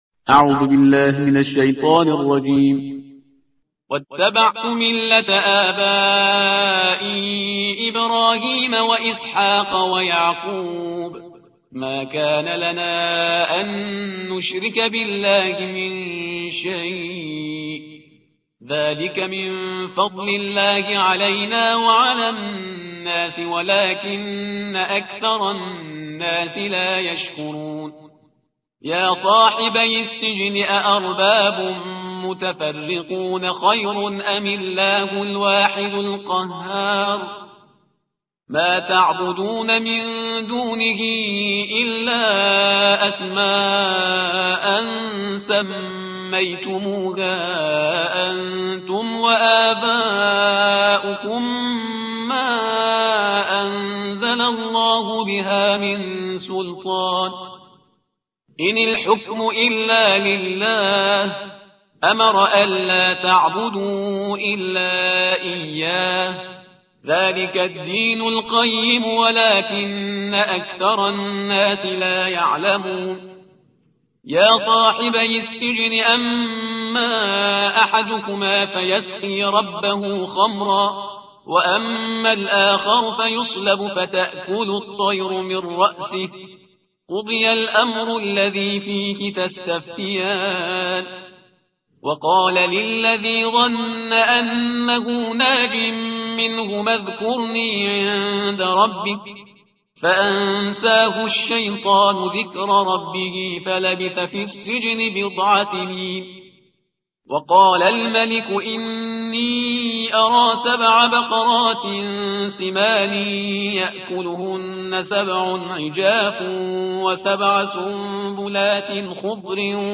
ترتیل صفحه 240 قرآن